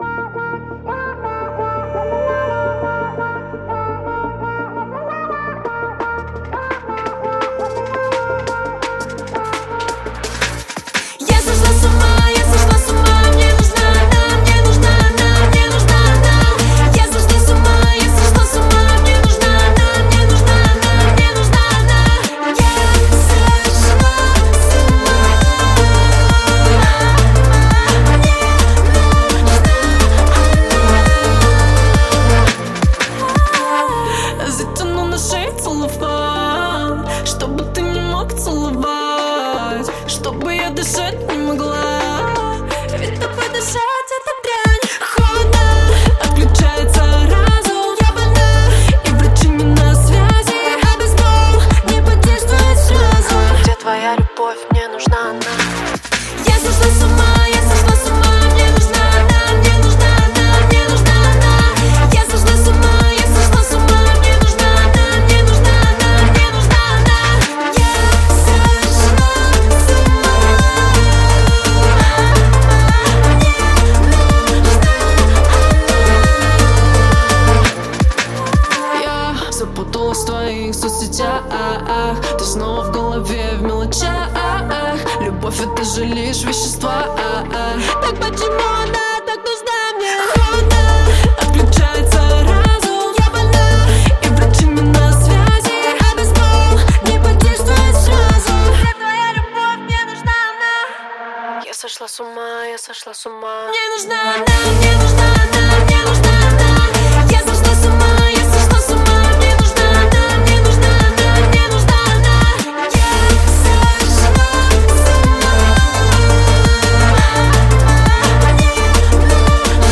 • Жанр песни: Жанры / Поп-музыка